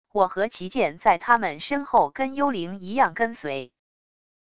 我和旗舰在他们身后跟幽灵一样跟随wav音频生成系统WAV Audio Player